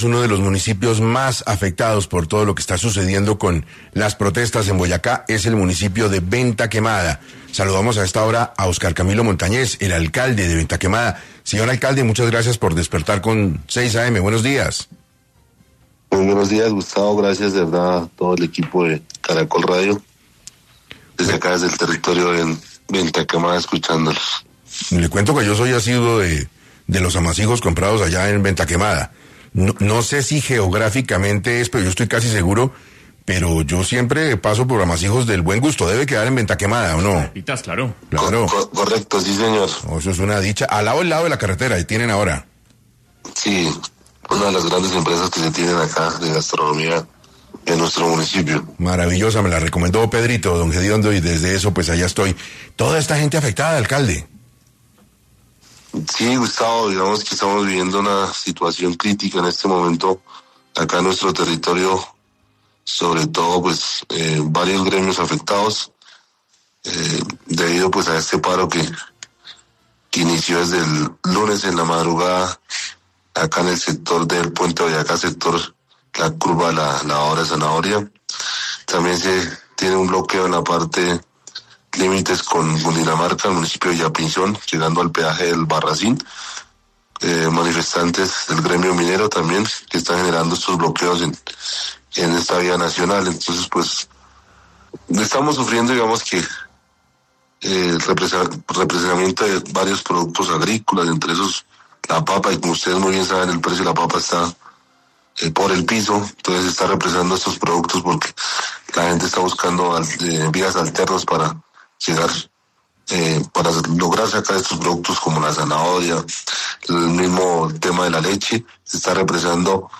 Óscar Camilo Montañez, alcalde de Ventaquemada, Boyacá, estuvo en 6AM para hablar de las afectaciones del paro en este departamento.